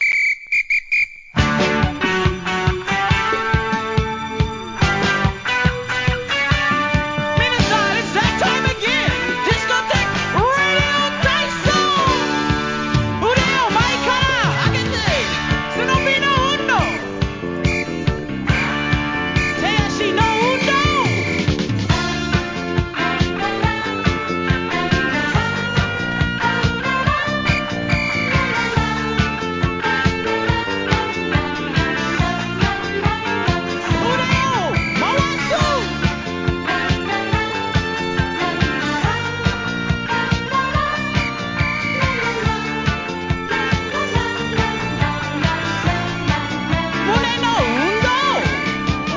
SOUL/FUNK/etc...
カタコトの日本語たまりません!!